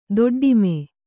♪ doḍḍime